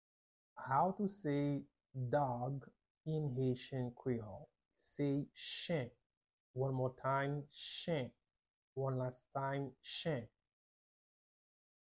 Pronunciation:
8.How-to-say-Dog-in-Haitian-Creole-–-chen-with-Pronunciation.mp3